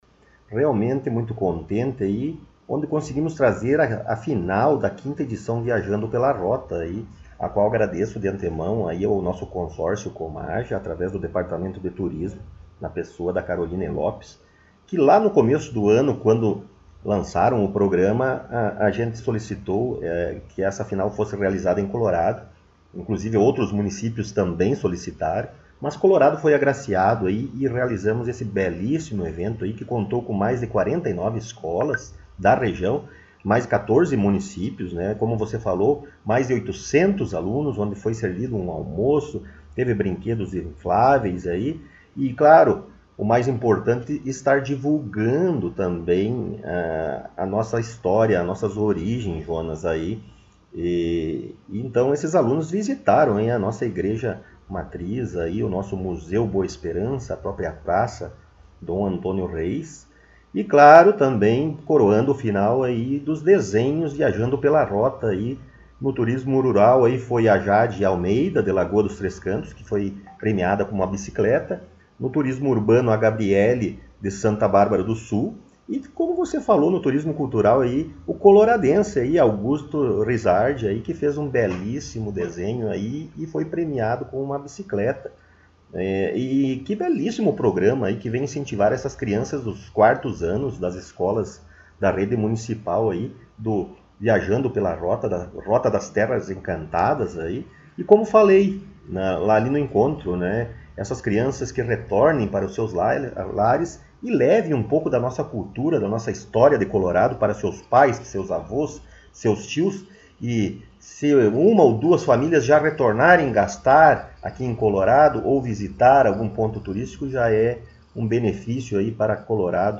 Prefeito Municipal Rodrigo Sartori concedeu entrevista
Estivemos mais uma vez em entrevista com o prefeito Rodrigo Sartori em seu gabinete.